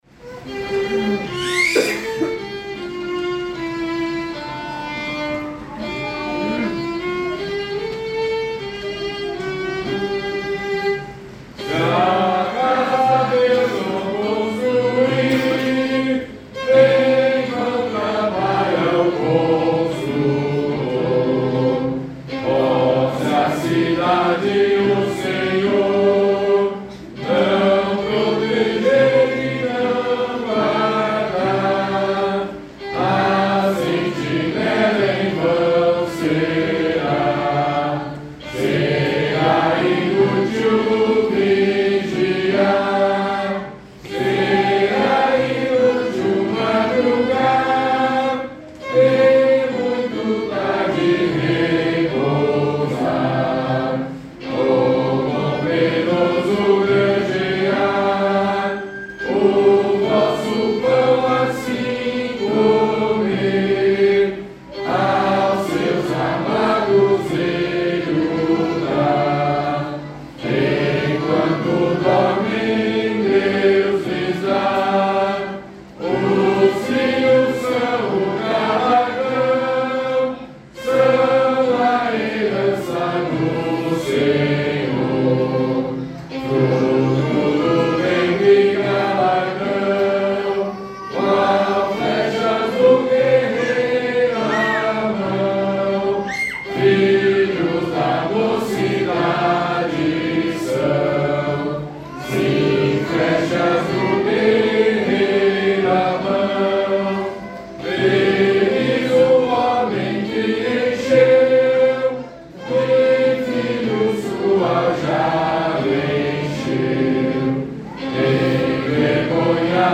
Métrica: 8 8. 8 8. 8 8
Modo: hipomixolídico
Harmonização: Claude Goudimel, 1564
salmo_127A_cantado.mp3